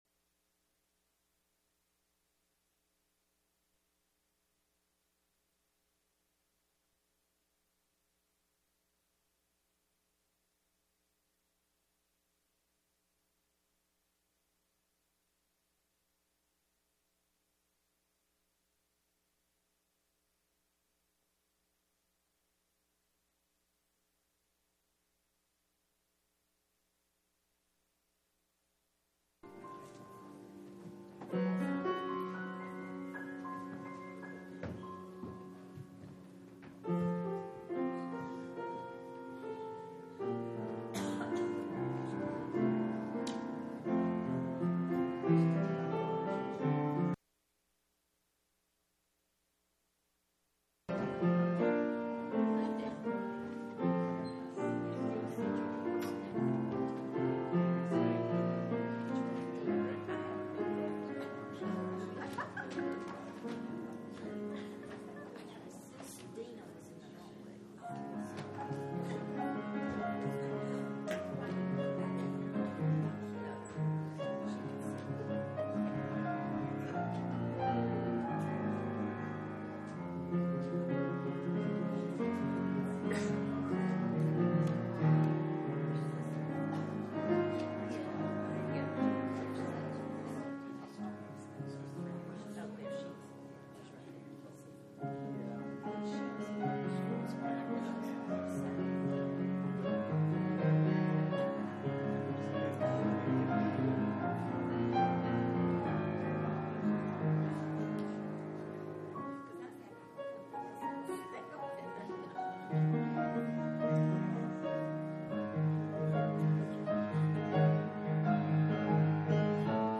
John 14:25-31 Service Type: Sunday Morning